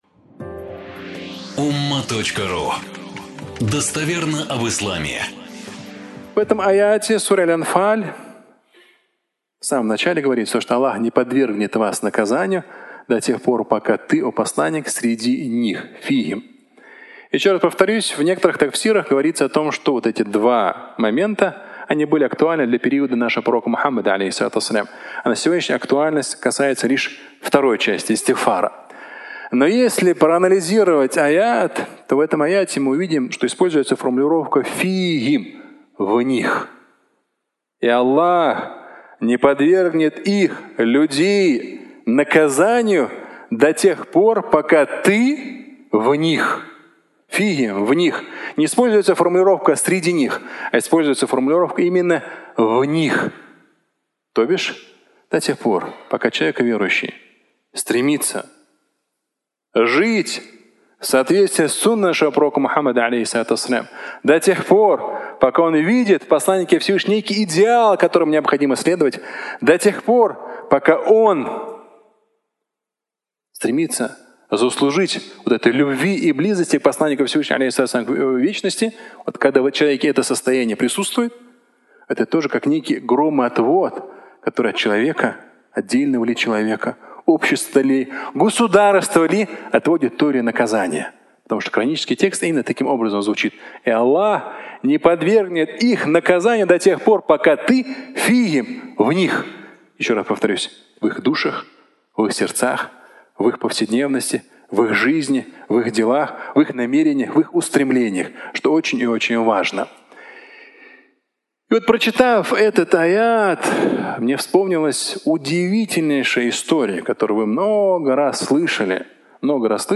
Пятничная проповедь